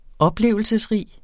Udtale [ ˈʌbˌlewˀəlsəs- ]